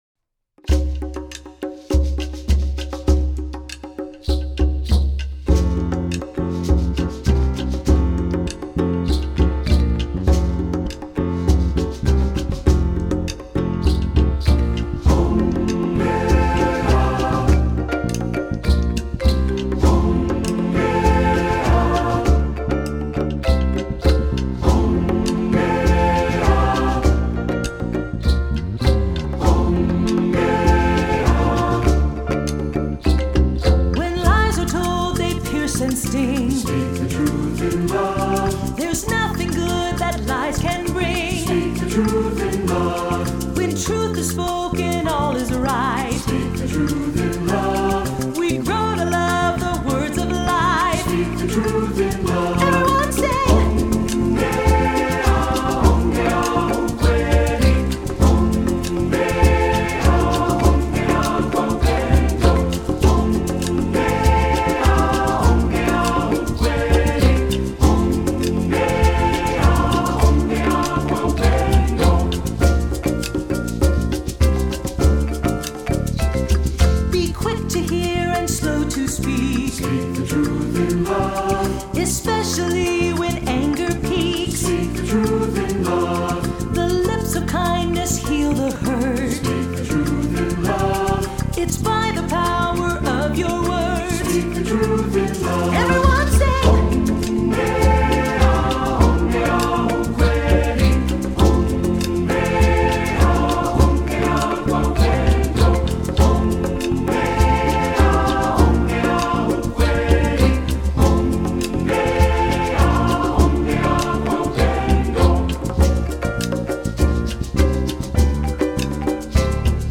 Voicing: SATB/CONGA